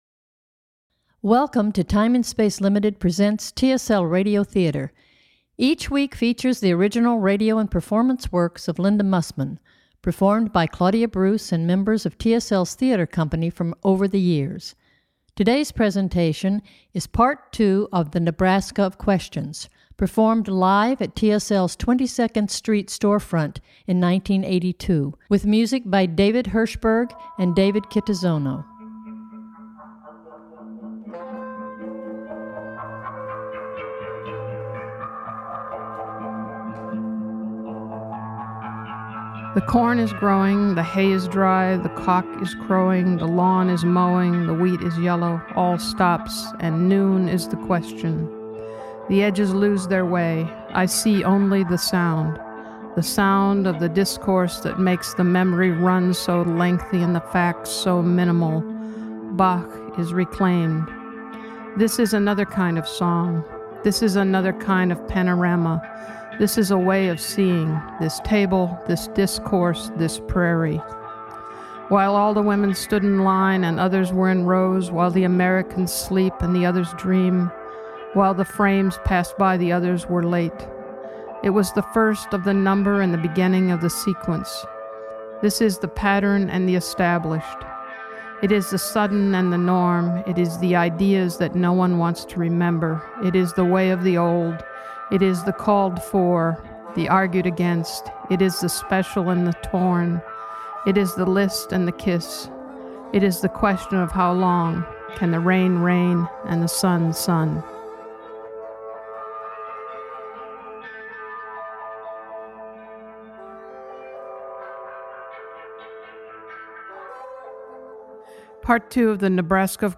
TSL RADIO THEATER - NEBRASKA OF QUESTIONS PART 2 RADIO EDIT.mp3